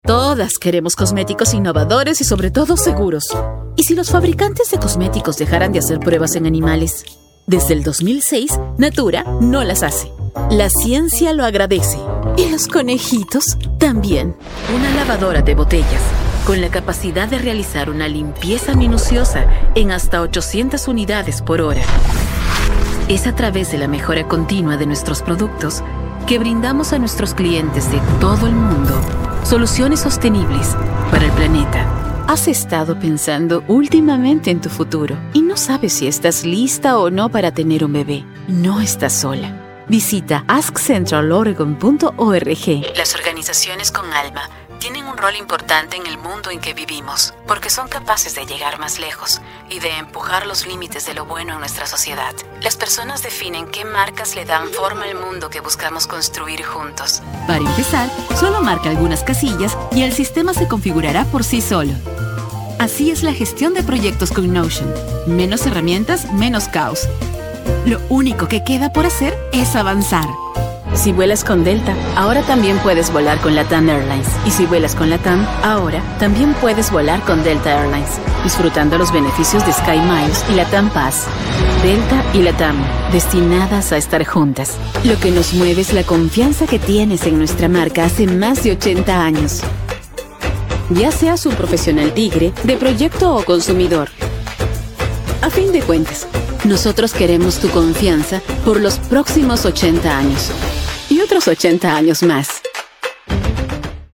Engage and captivate your audience with a clear and persuasive voice.